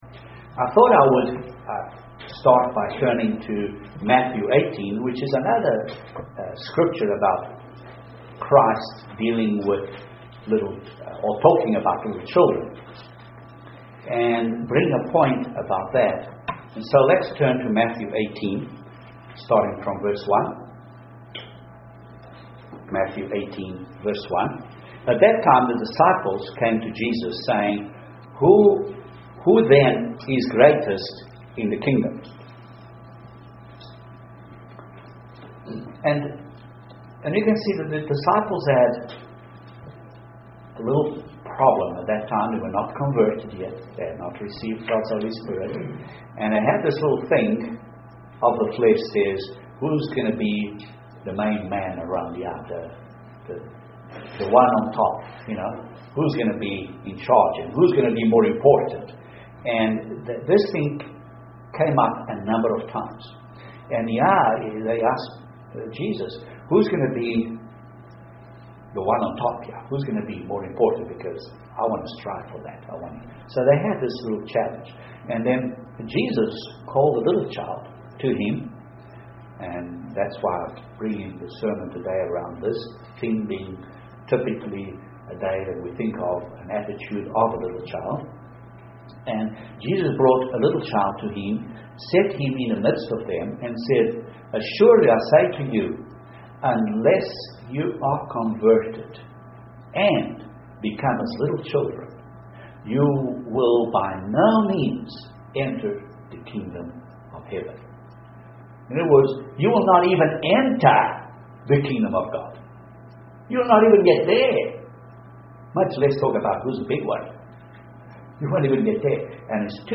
Lessons from a young king UCG Sermon Transcript This transcript was generated by AI and may contain errors.